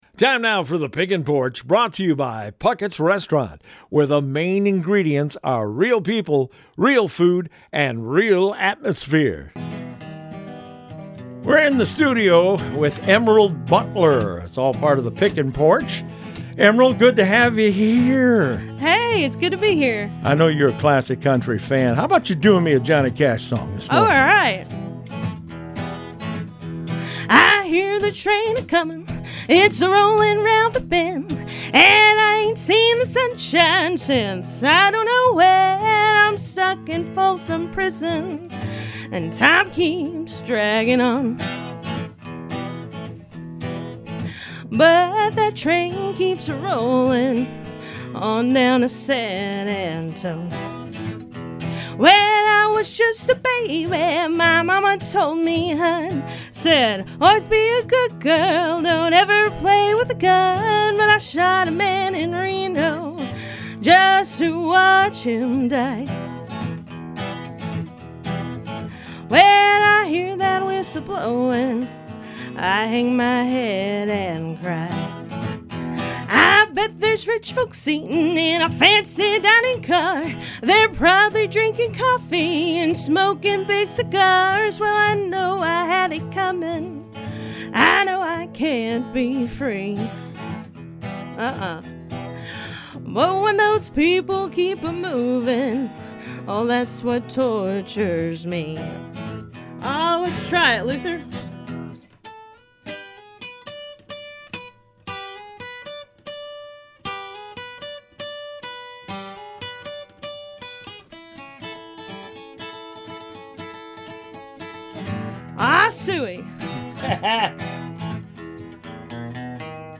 classic country